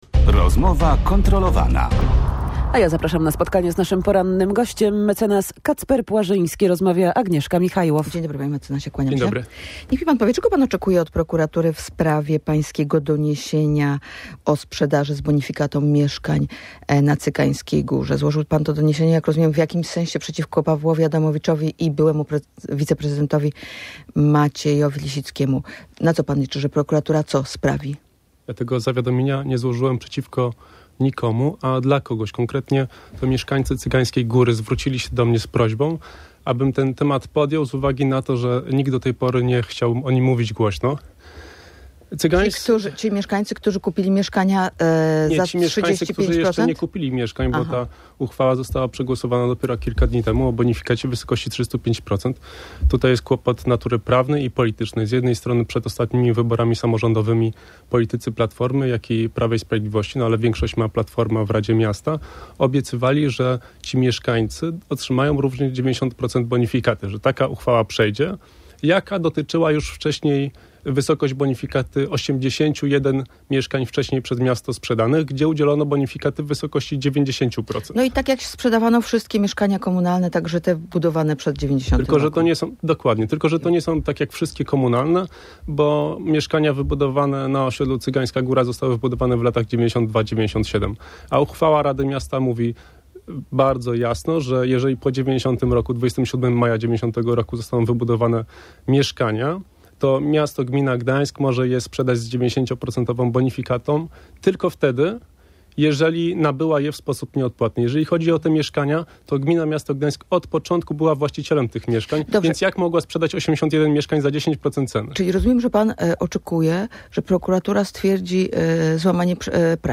– To nikczemne posunięcie, że niektórzy mogli wykupić mieszkanie za 65 proc. wartości, a inni jedynie za 10 proc. Często pokrzywdzeni zostali ludzie, którym dwadzieścia lat temu obiecywano, że takie bonifikaty otrzymają – mówił gość Radia Gdańsk.